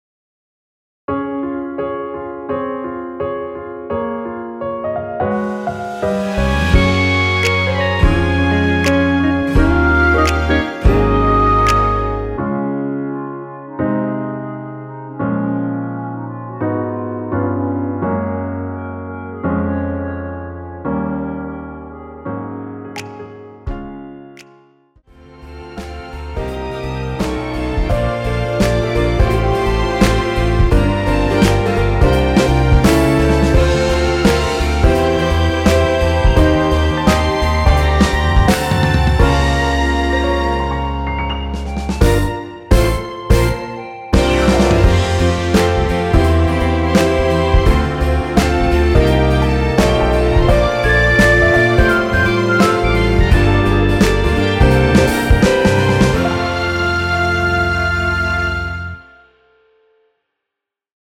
엔딩이 페이드 아웃이라 노래 하기 좋게 엔딩을 만들어 놓았습니다.(미리듣기 참조)
원키 멜로디 포함된 MR입니다.
Db
앞부분30초, 뒷부분30초씩 편집해서 올려 드리고 있습니다.